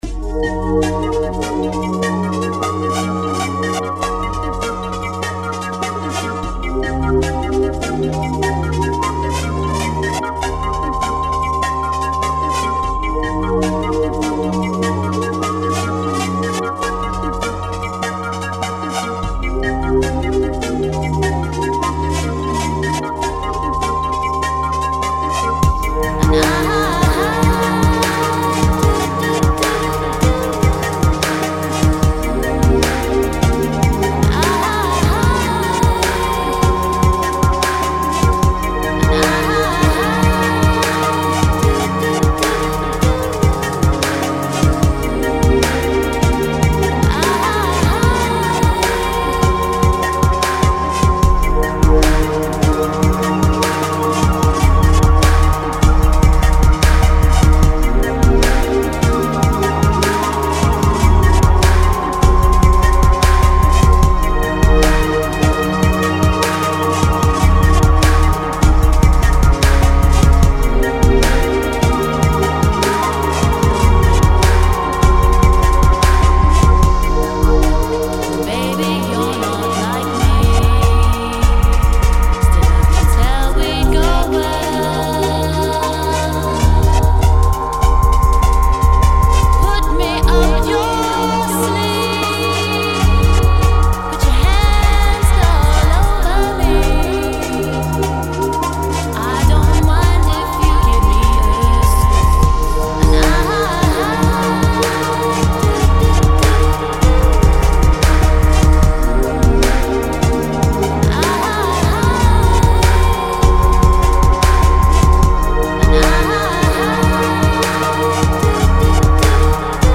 dance/electronic
Laid back summer vocals